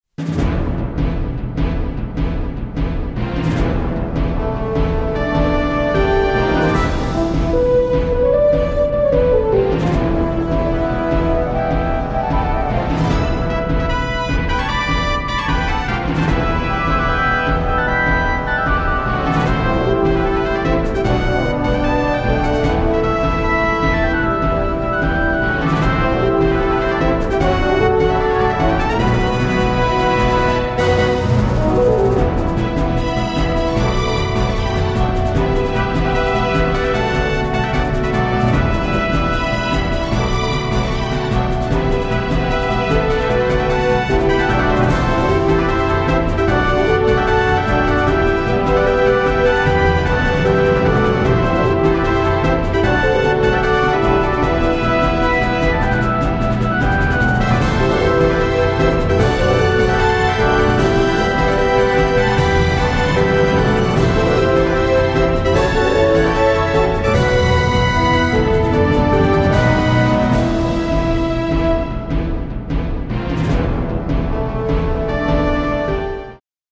緊迫・軍隊